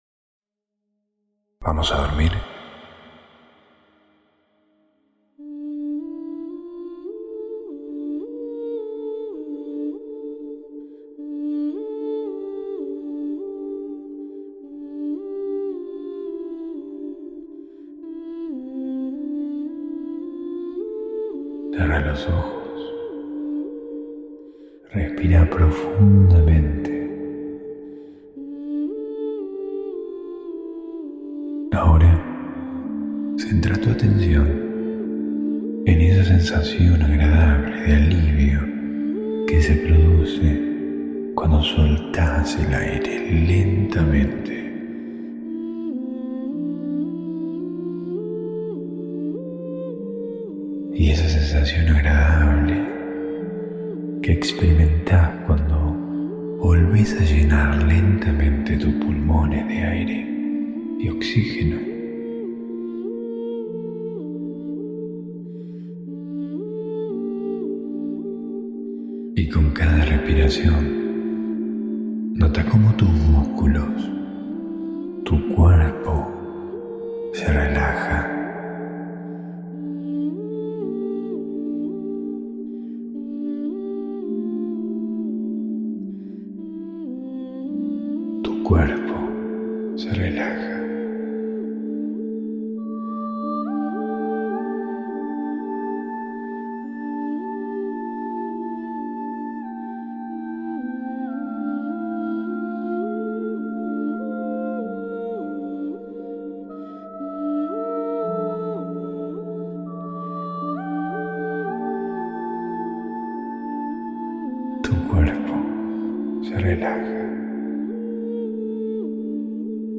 Hipnosis para Dormir sin Ansiedad
[Altamente recomendable escucharlo con auriculares ] Hosted on Acast.